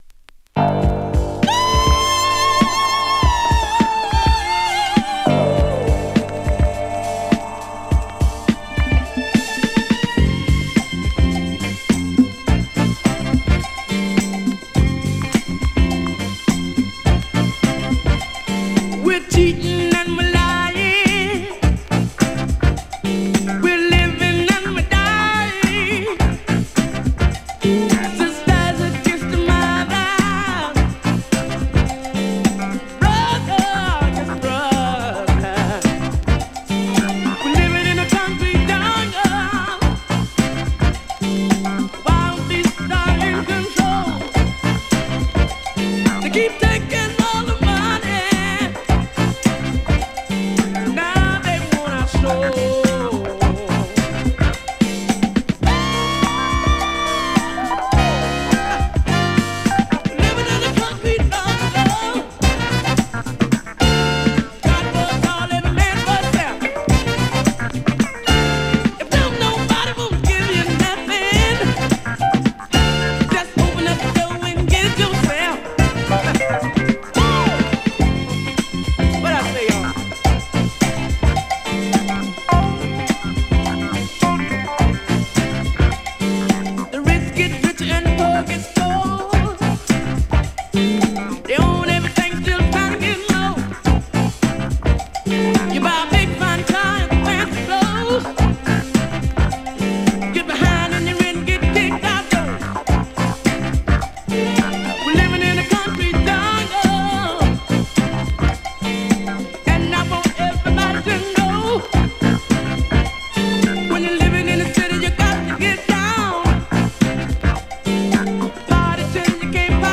rare groove,jazz funkの有名どころを網羅したコンピ。